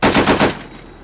.50 cal bursts
Isolated from a test firing range video.
Burst lengths vary a little, it will give your unit a little variety so as not to sound repetitive.